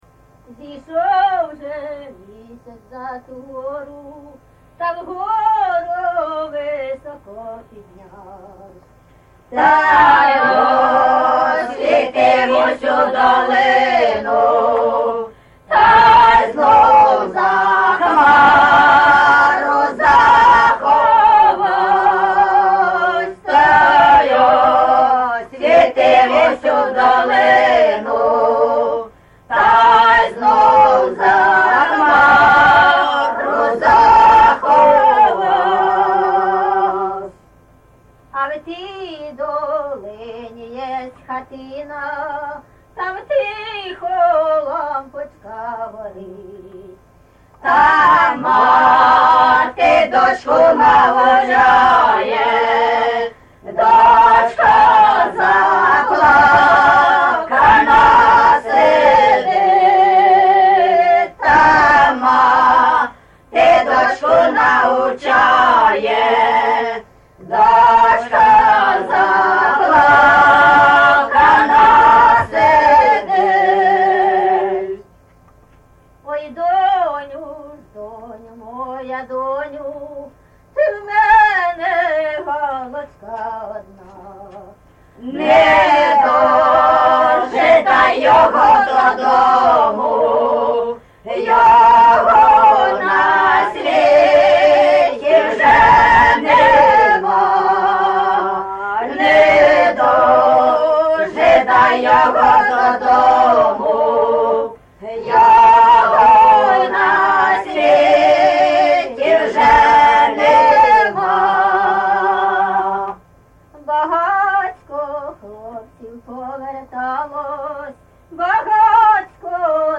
ЖанрПісні з особистого та родинного життя
Місце записус. Лука, Лохвицький (Миргородський) район, Полтавська обл., Україна, Полтавщина